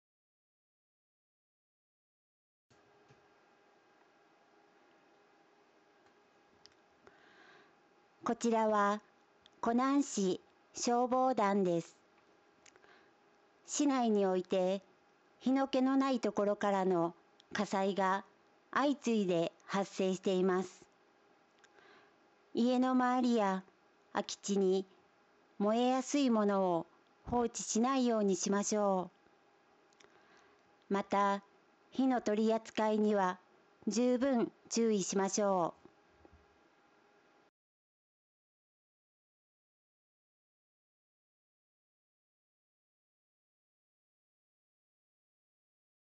火災多発に対する注意喚起音声データ (音声ファイル: 1.1MB)